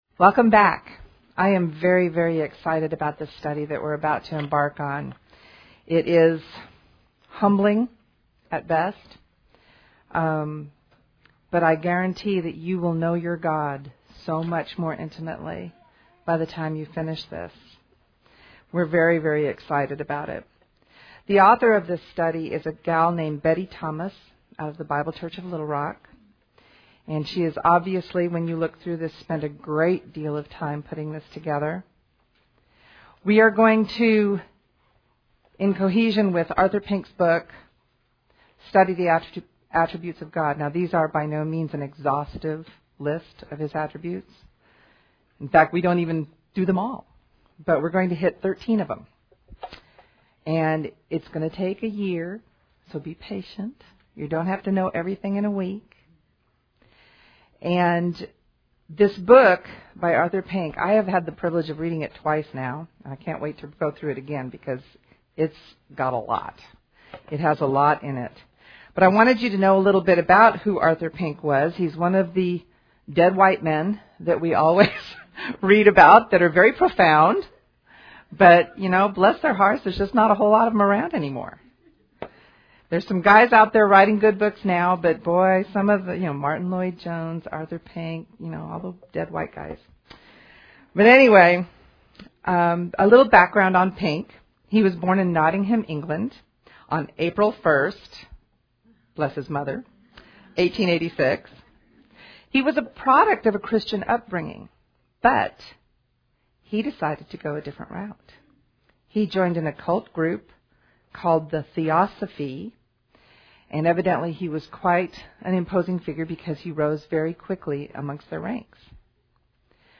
Women Women - Bible Study